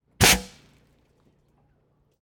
air_gun_1.L.wav